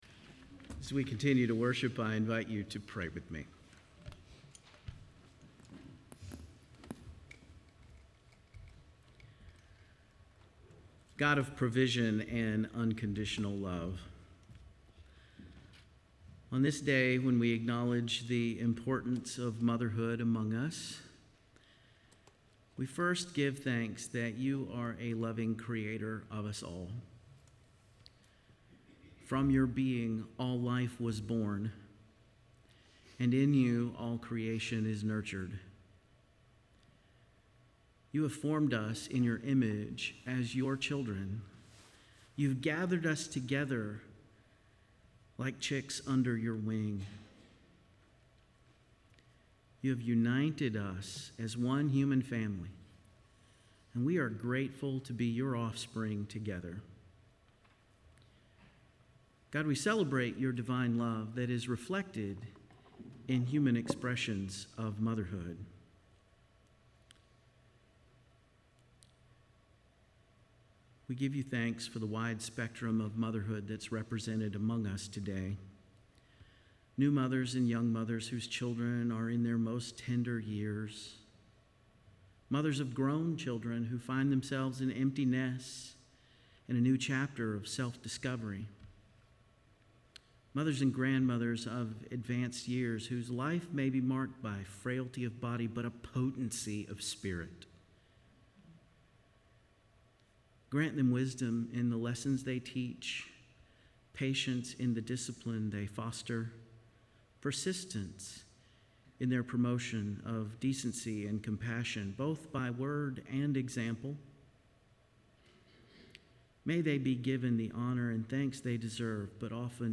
John 20:19-31 Service Type: Guest Preacher Bible Text